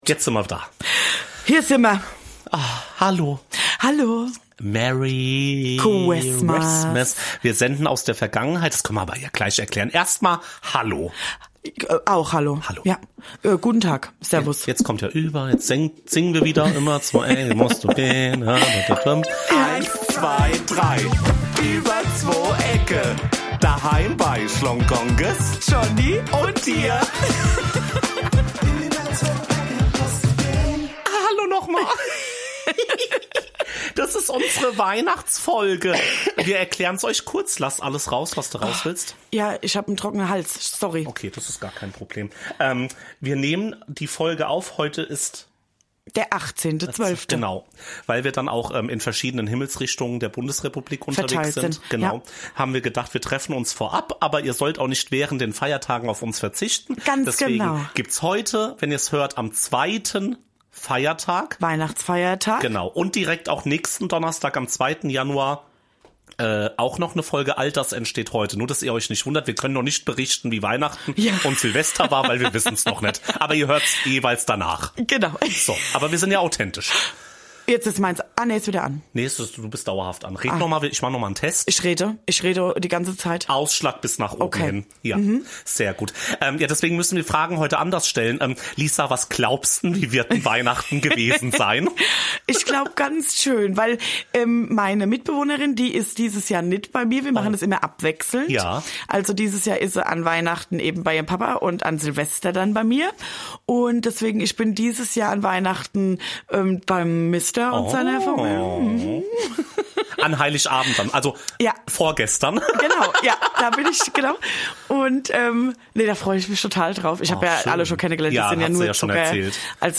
Wir nutzen die Folge um euch DANKE zu sagen! Deshalb rufen wir euch an und bringen das Dankeschön einfach direkt vorbei!